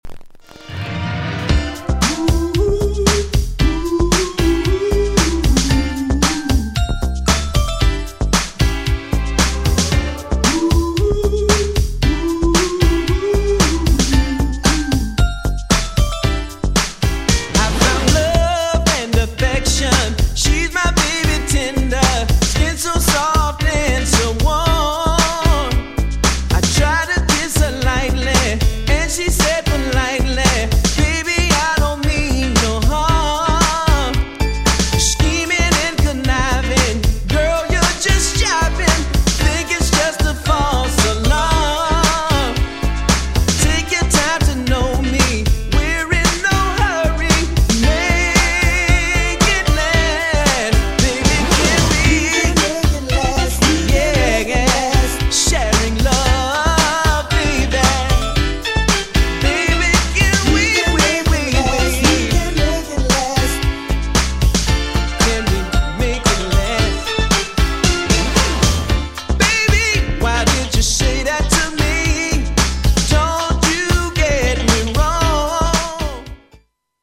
R&B MIXもイケル!!
GENRE House
BPM 111〜115BPM